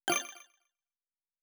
Special & Powerup (43).wav